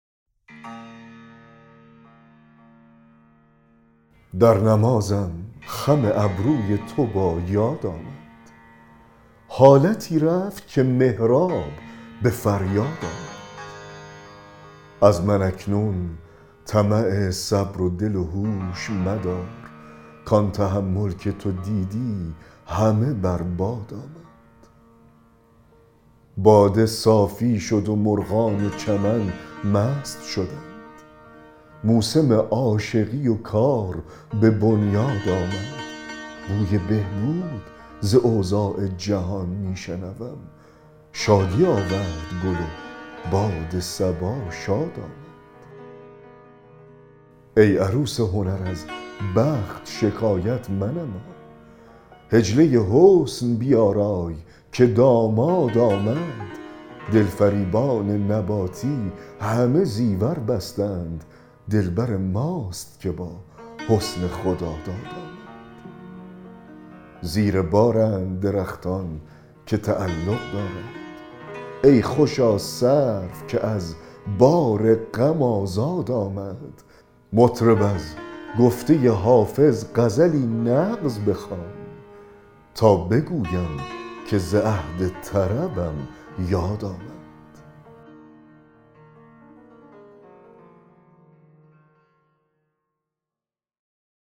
دکلمه غزل 173 حافظ
دکلمه-غزل-173-حافظ-در-نمازم-خم-ابروی-تو-با-یاد-آمد.mp3